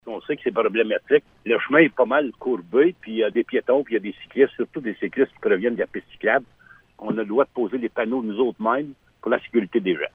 Cependant, cette avenue n’était pas possible, comme l’indique le maire de Blue Sea, Laurent Fortin :